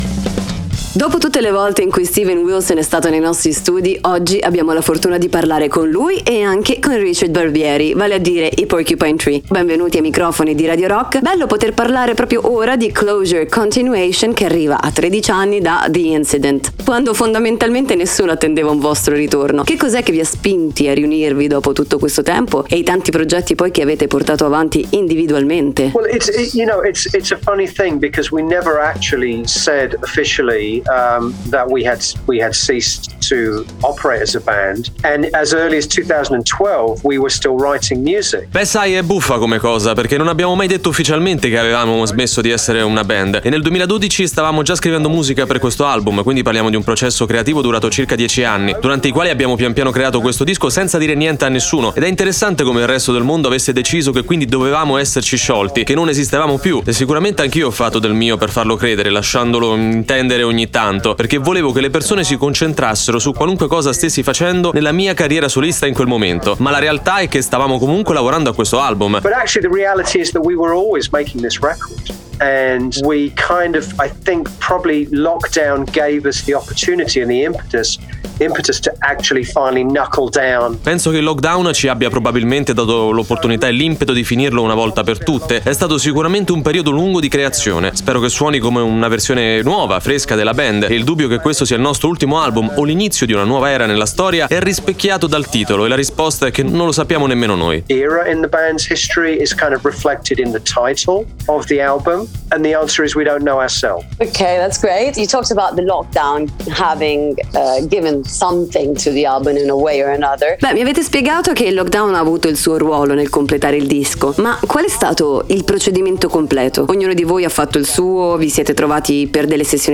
Interviste: Porcupine Tree (27-05-22)